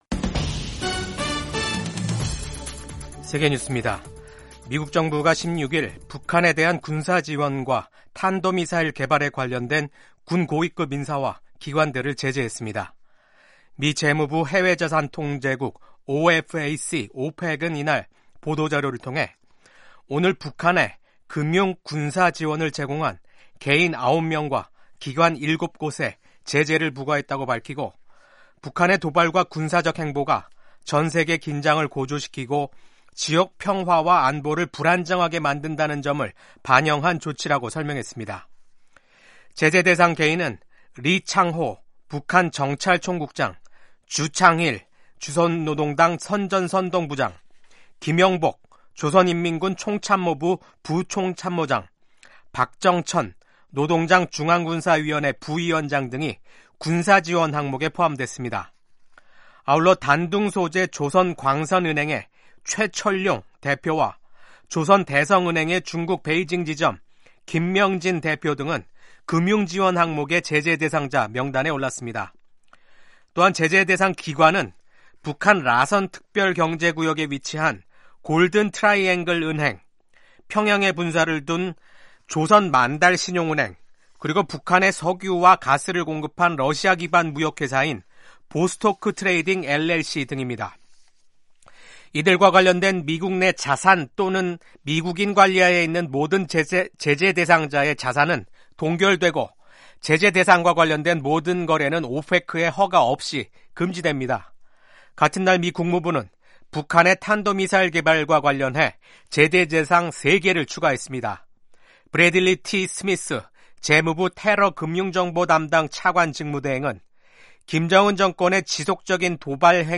세계 뉴스와 함께 미국의 모든 것을 소개하는 '생방송 여기는 워싱턴입니다', 2024년 12월 17일 아침 방송입니다. 이스라엘 정부가 국제법상 불법 점령 중인 골란고원에 정착촌을 더 확대하는 계획을 승인했습니다. ‘12.3 비상계엄’ 사태로 탄핵소추된 윤석열 한국 대통령에 대한 탄핵심판이 27일 시작됩니다. 유럽연합(EU)이 역내 자유로운 이동을 보장하는 솅겐조약 정식 회원국으로 불가리아와 루마니아의 가입을 최종 승인했습니다.